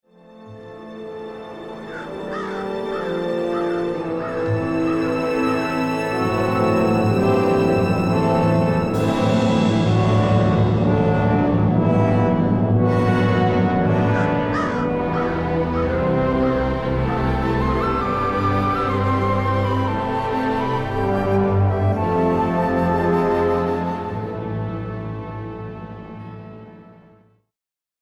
chinese bamboo flute, special effects
orchestra